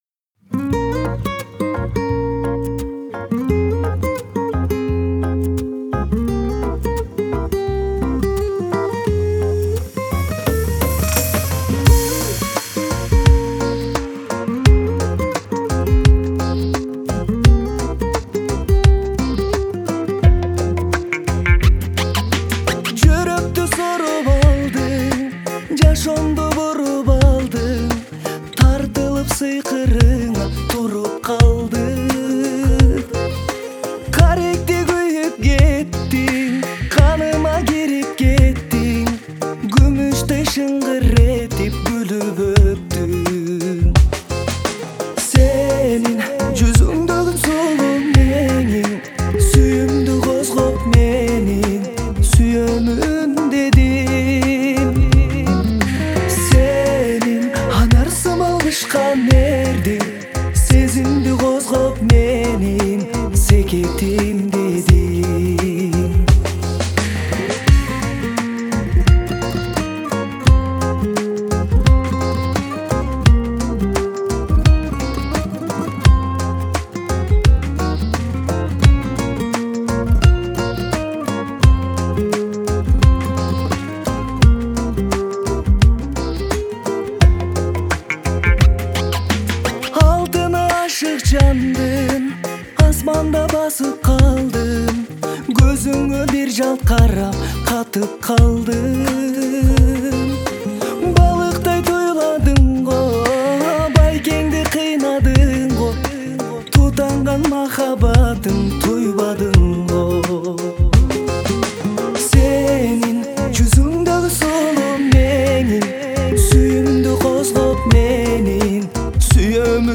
Трек размещён в разделе Русские песни / Киргизская музыка.